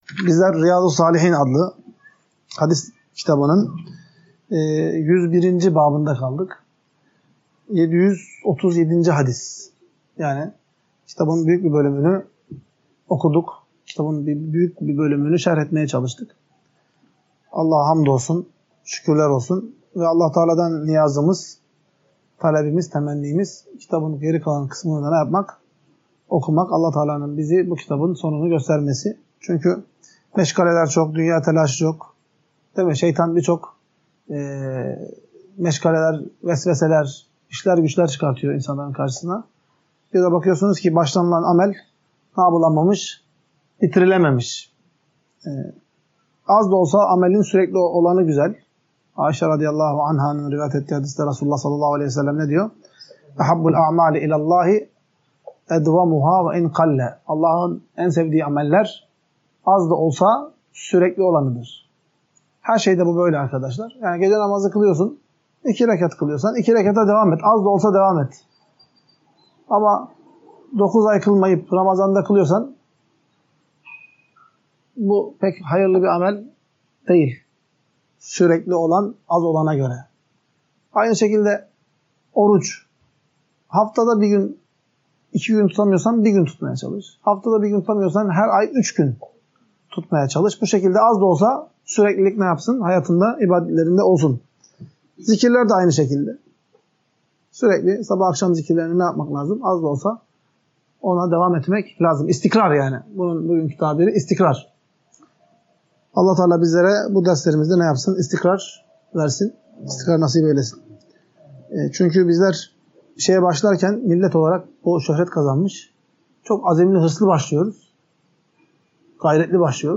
Ders - 2. Bölüm – Yemeğe Kusur Bulmayıp Beğendiğini Ifade Etmenin Müstahap Olduğu